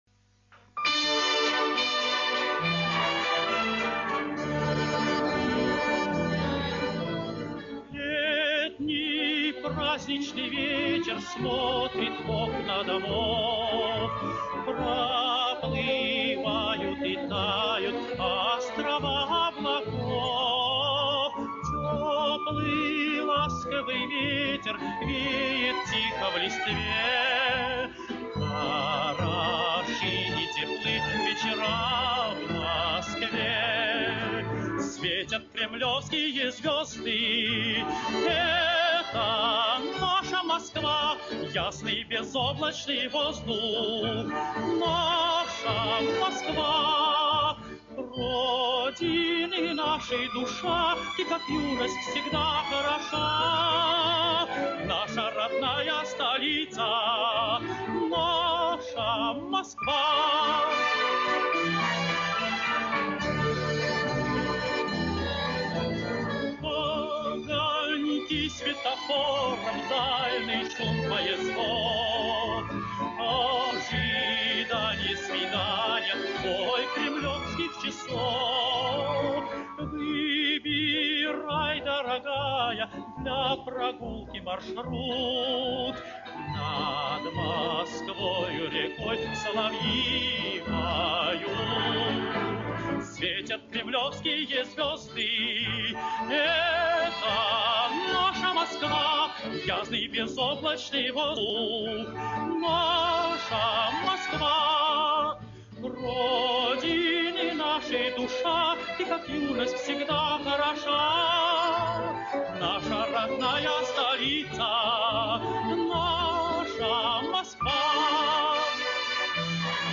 Исполняет песню певец(тенор)